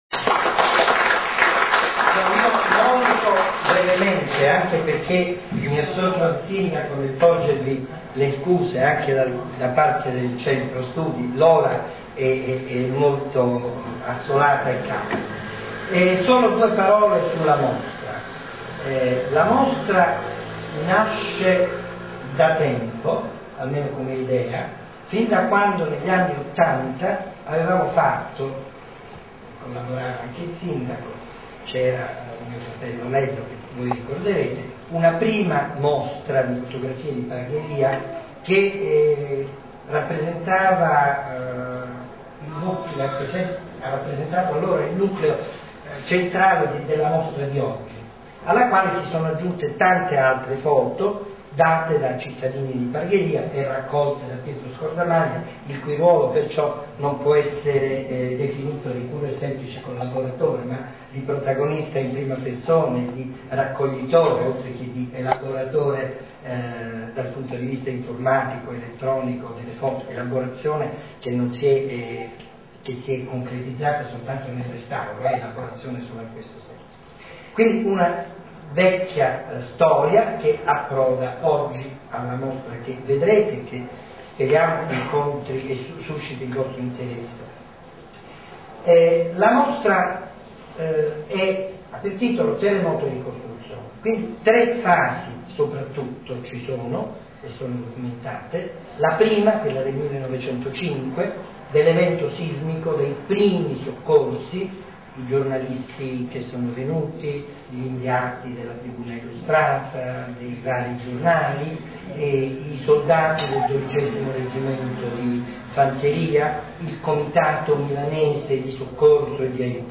Inaugurazione  Mostra: "Terremoto e Ricostruzione 1905-1935"